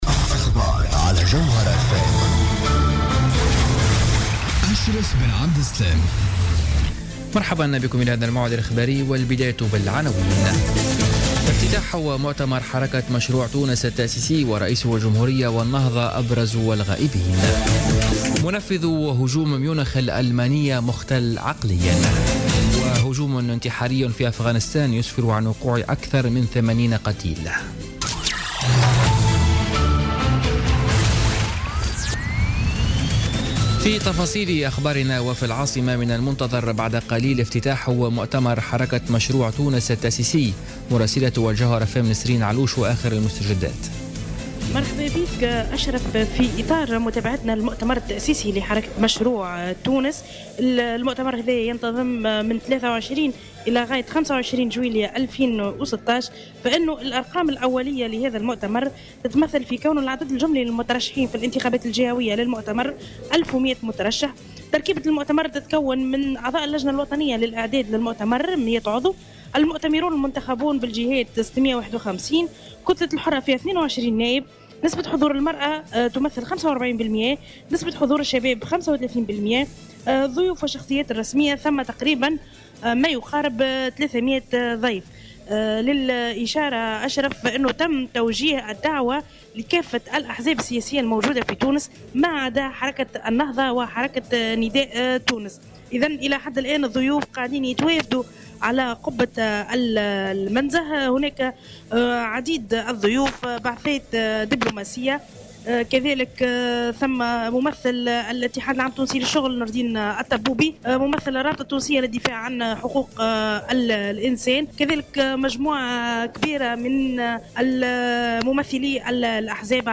نشرة أخبار السابعة مساء ليوم السبت 23 جويلية 2016